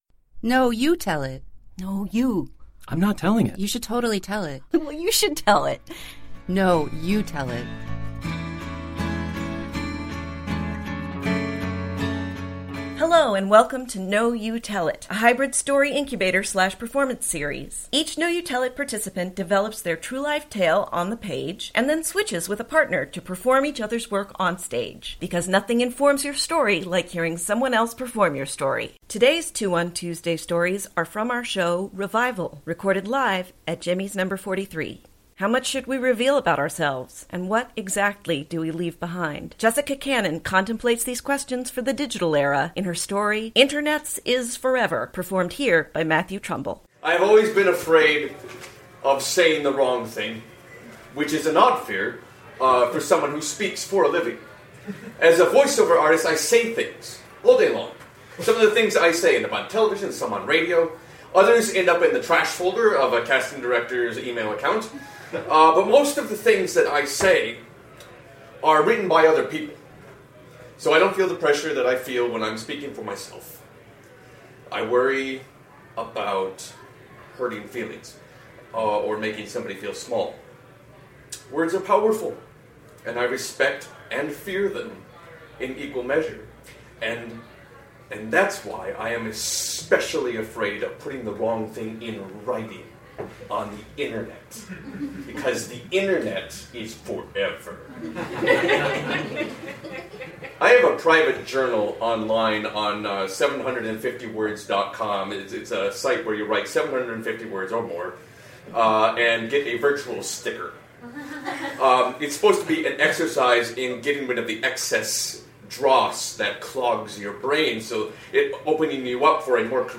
Today’s “Two on Tuesday” stories are from our ‘Revival’ show recorded live at Jimmy’s No. 43. How much should we reveal about ourselves?